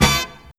horn_hit3.wav